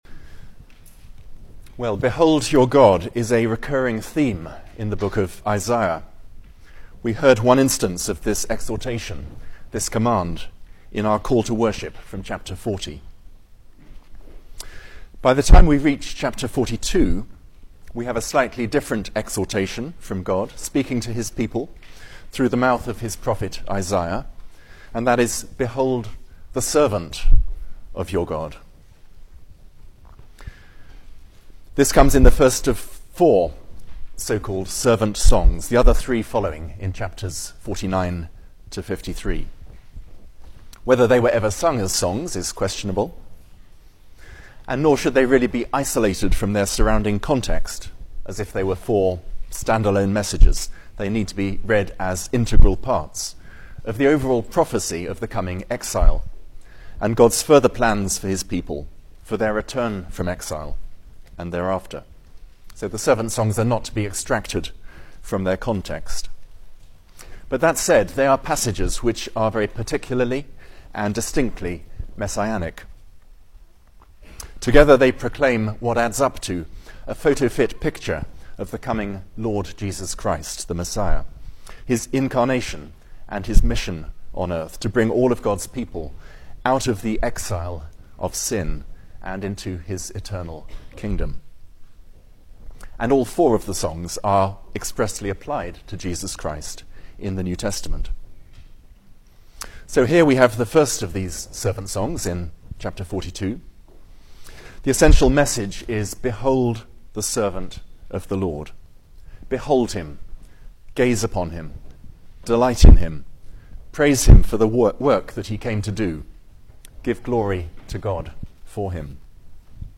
Service Type: Sunday Morning
Series: Single Sermons